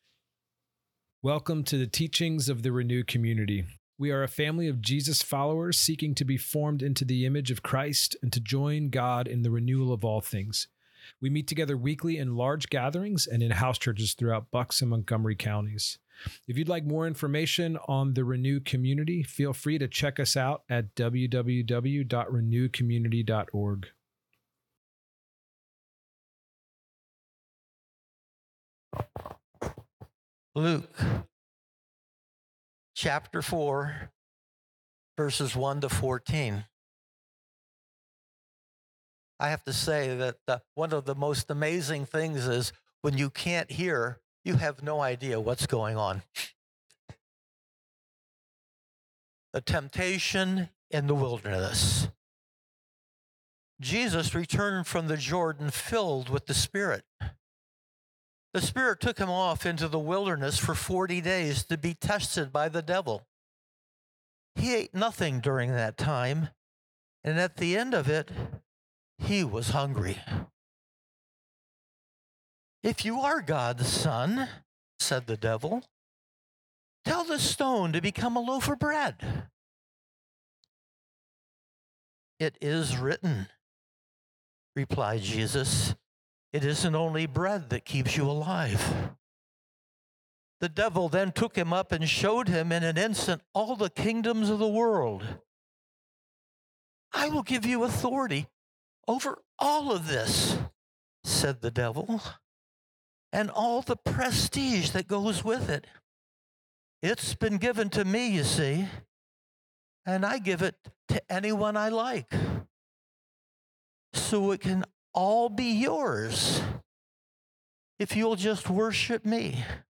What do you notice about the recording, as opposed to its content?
The Renew Community is a Jesus community for skeptics and dreamers, the hurting and the hungry located in Lansdale, PA. These are teachings from our gatherings and thoughts and stories from The InBetween.